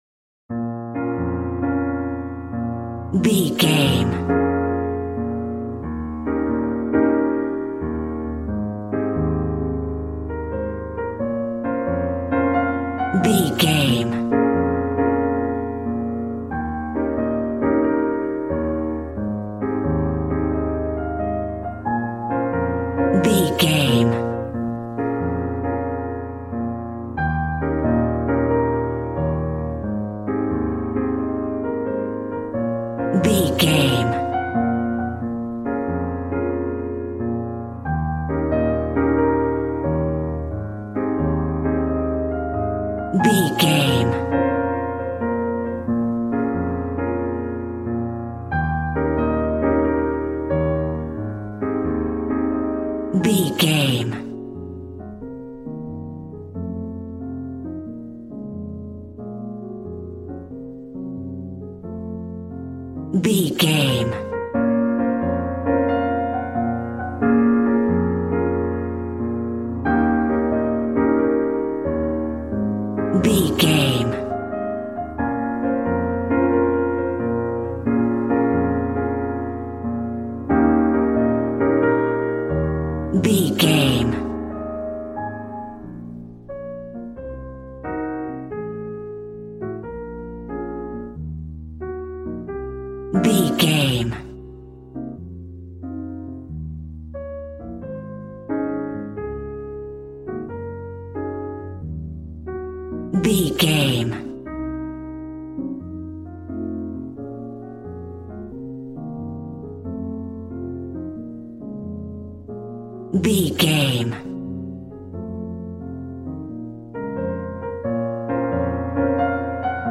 Smooth jazz piano mixed with jazz bass and cool jazz drums.,
Aeolian/Minor
B♭
smooth
piano
drums